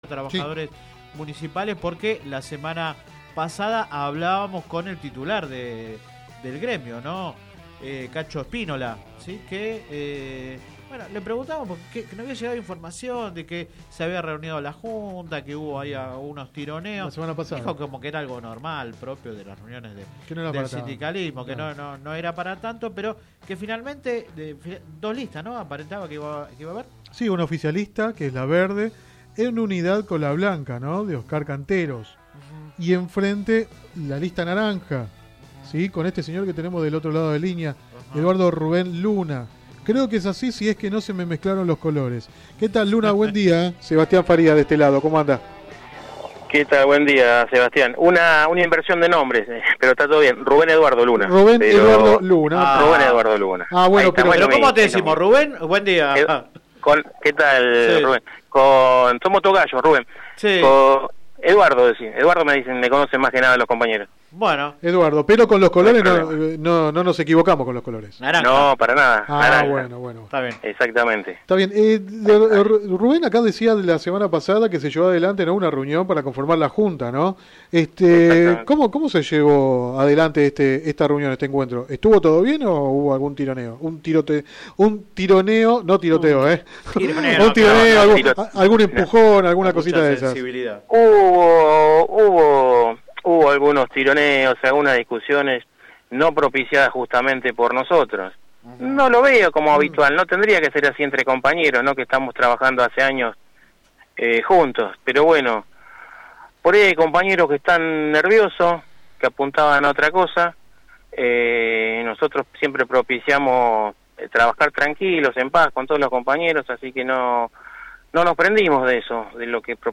en conversación con el programa radial Sin Retorno